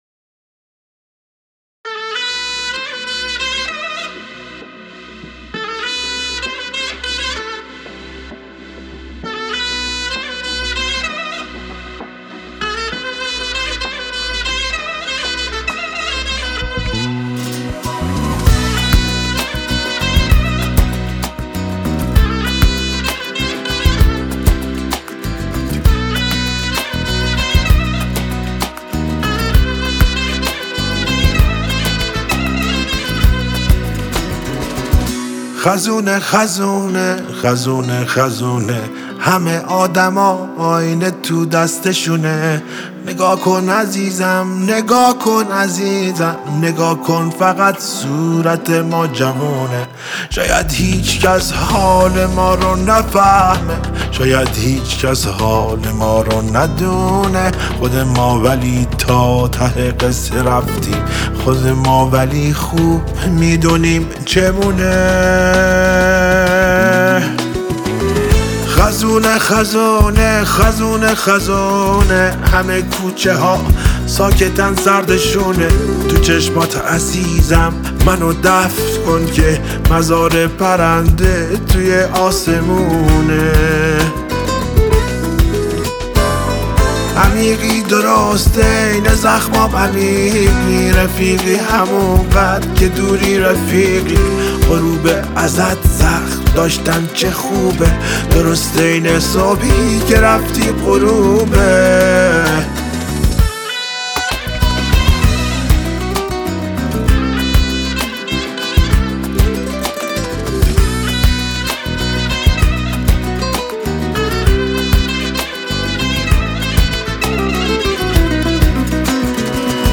صدای گرم و گیرای
این قطعه‌ی احساسی